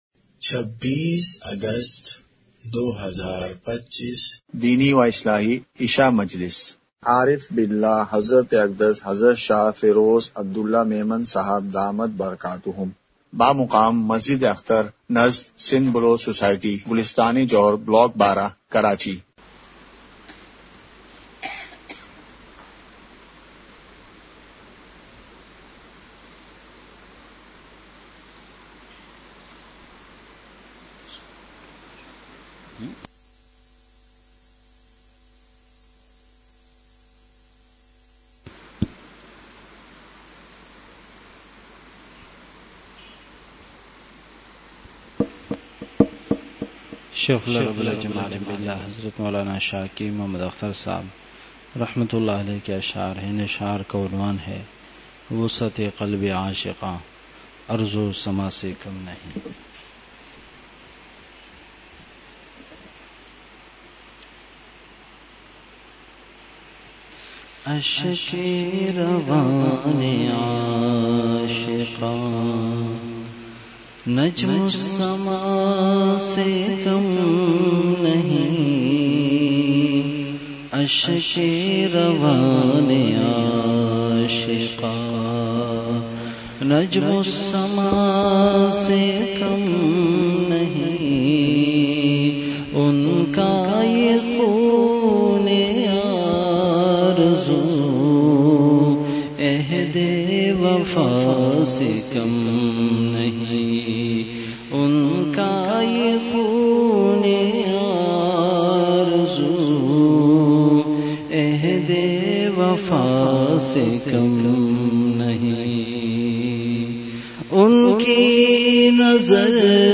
مقام:مسجد اختر نزد سندھ بلوچ سوسائٹی گلستانِ جوہر کراچی
05:00) بیان سے قبل اشعار ہوئے۔۔ 06:03) نفس و شیطان کا فرق۔۔